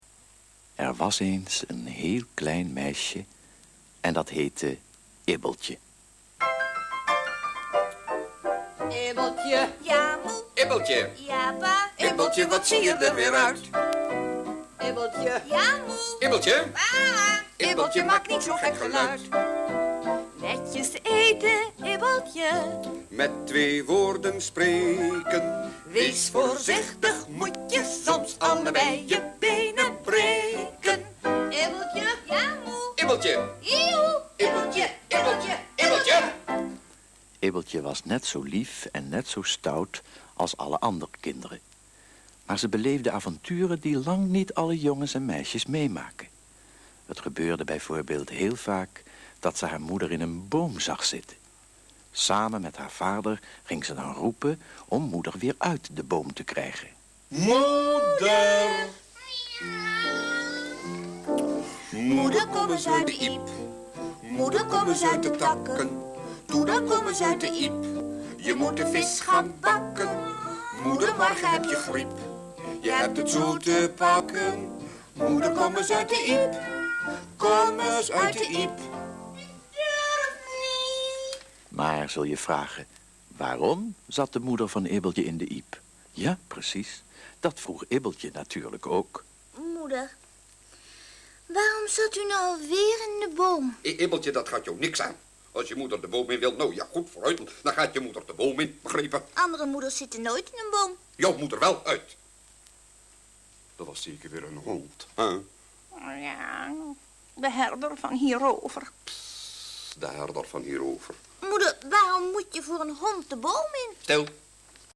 Een muzikaal verhaal
Muzikaal hoorspel